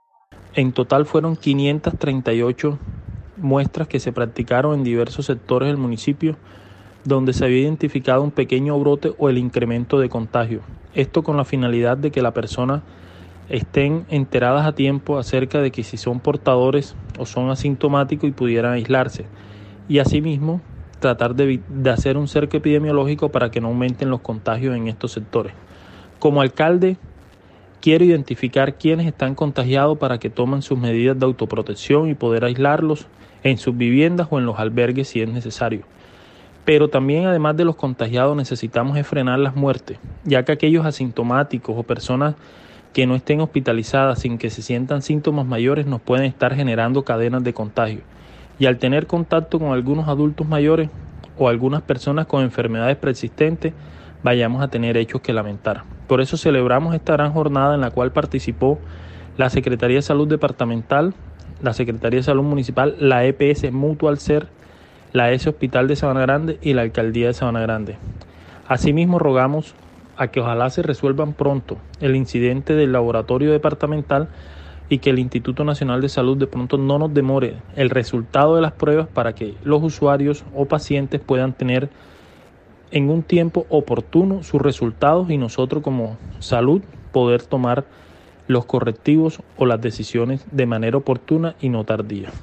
VOZ-ALCALDE-538-MUESTRAS.mp3